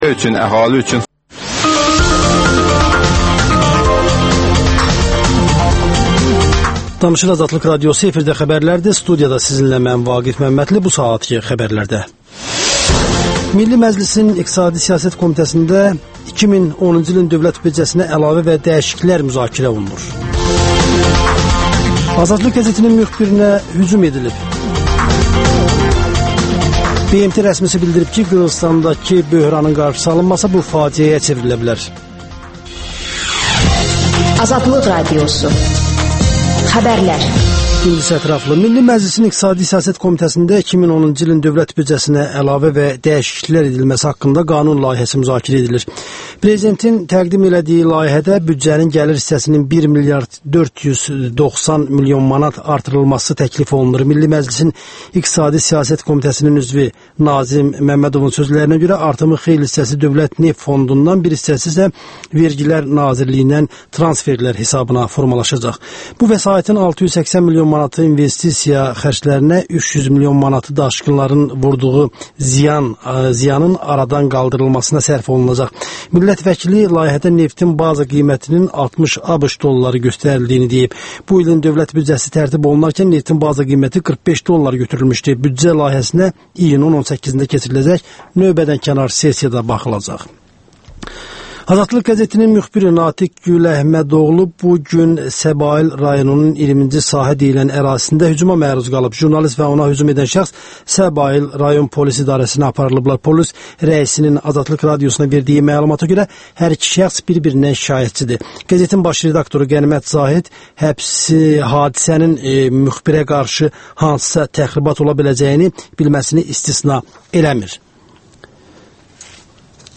İşdən sonra - Deputat Həvva Məmmədova ilə söhbət